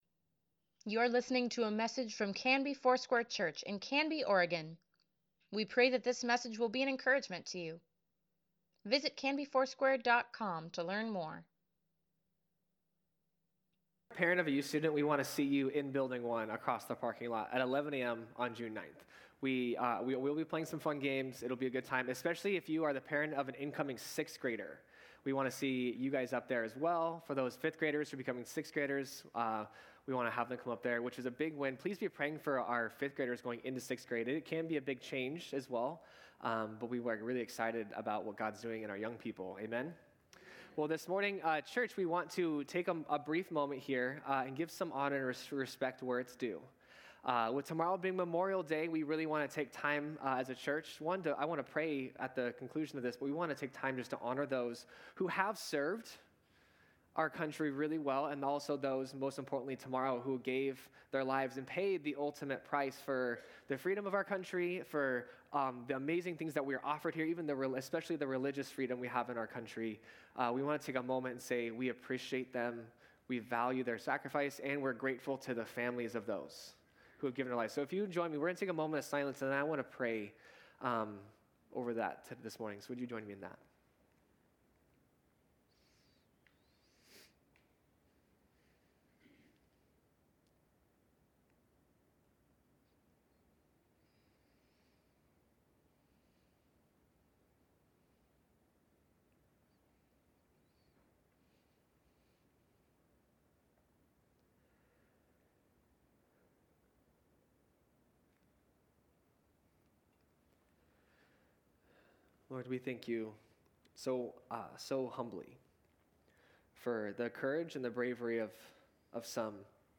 Sunday Sermon | May 26, 2024